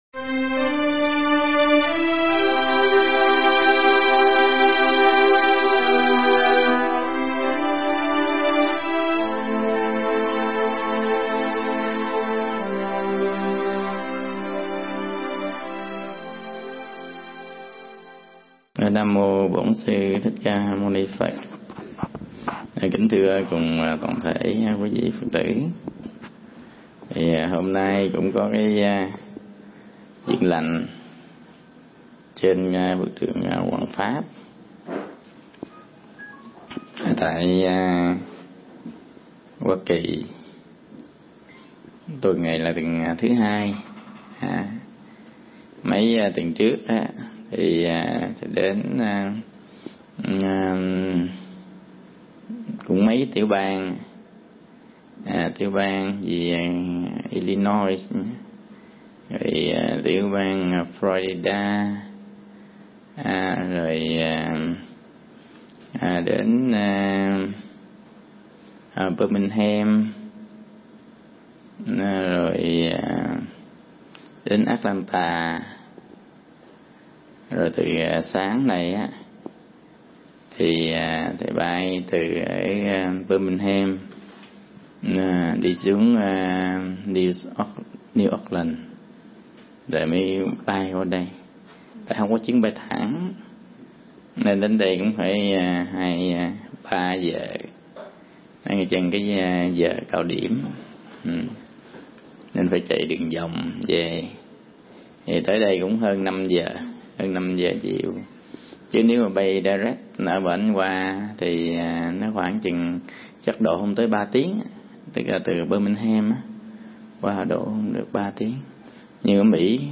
Mp3 Pháp Thoại Cuộc sống ở Mỹ và Chuyện Đạo